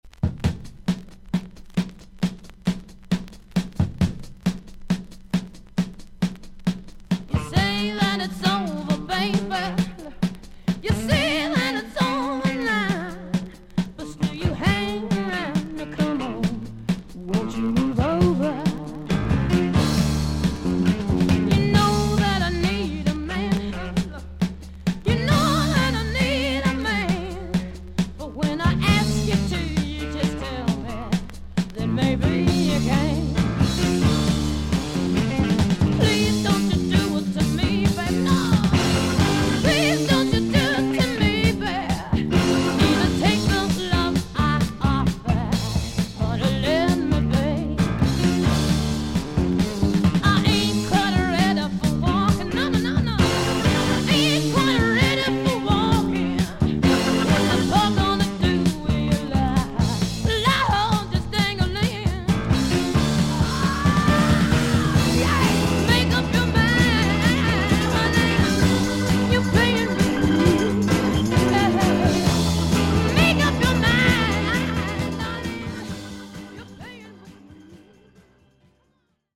少々軽いパチノイズの箇所あり。少々サーフィス・ノイズあり。クリアな音です。
女性ロック・シンガー。